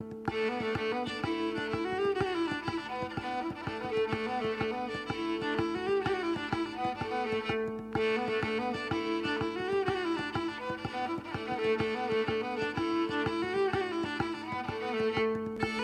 Audición de diferentes sonidos de la familia de cuerda frotada.
Rabel